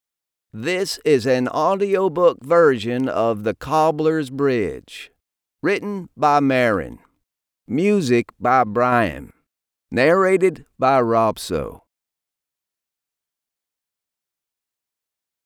The-Cobblers-Bridge_OpeningCredits.mp3